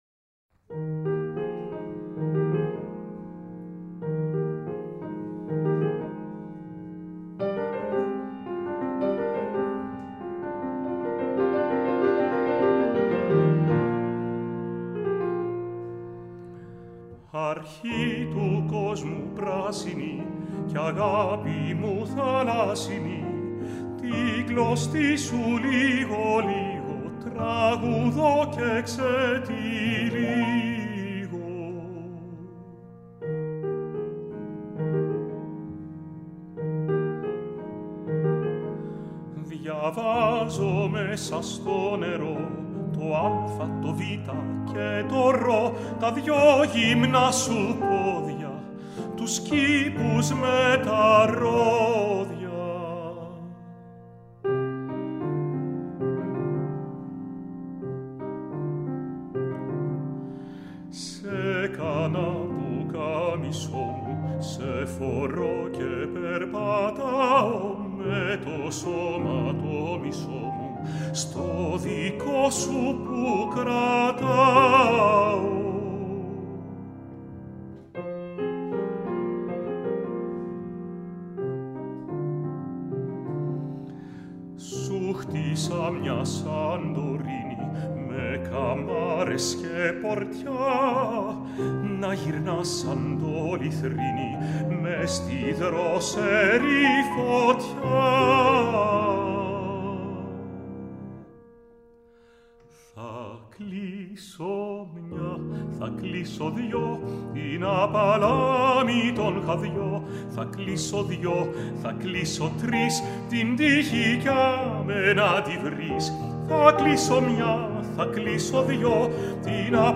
Συμπράττουν δύο εξαίρετοι σολίστ του πιάνου